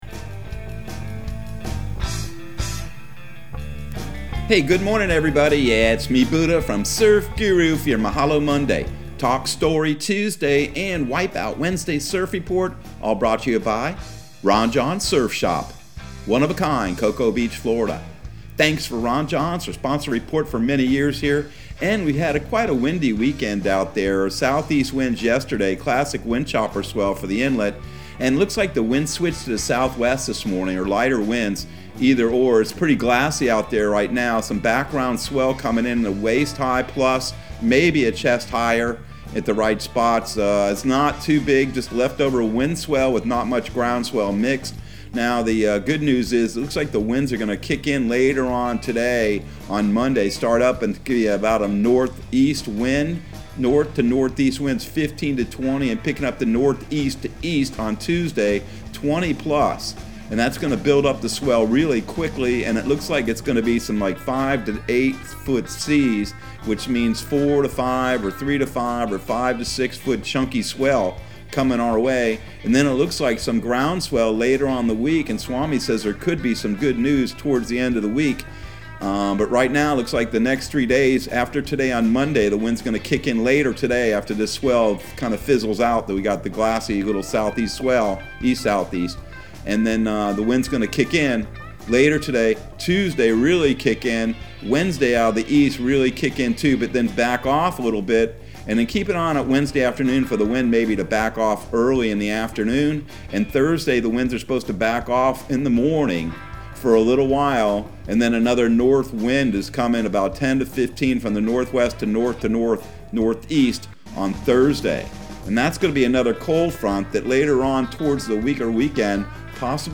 Surf Guru Surf Report and Forecast 01/10/2022 Audio surf report and surf forecast on January 10 for Central Florida and the Southeast.